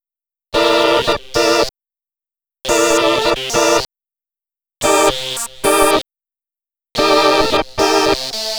Track 15 - Synth 03.wav